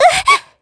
Ripine-Vox_Damage_kr_03.wav